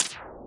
描述：高帽打击乐在PC上生成和处理。
标签： 产生 HH 高的帽子 四氯乙烯 打击乐器 处理 合成器
声道立体声